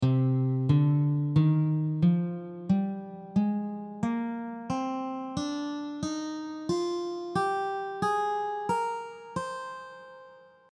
The examples will help you to hear the notes of each scale.
C Minor scale
minor-scale-audio.mp3